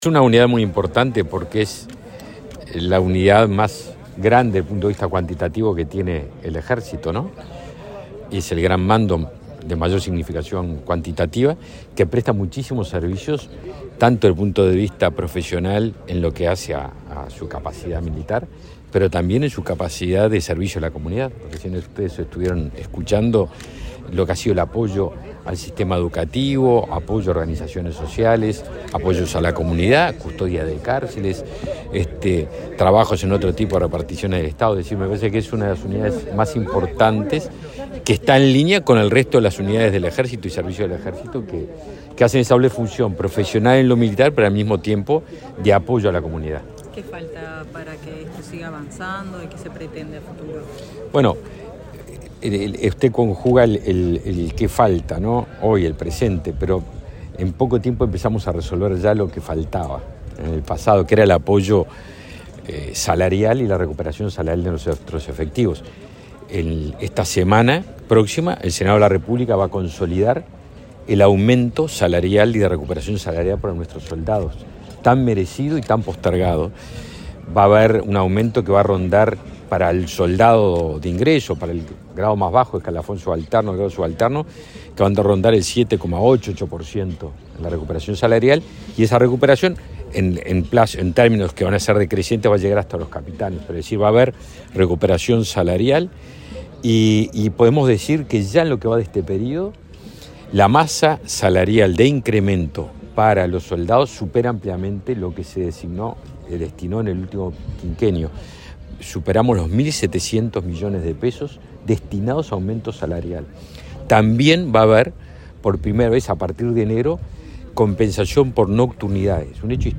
Declaraciones del ministro de Defensa Nacional, Javier García
Luego dialogó con la prensa.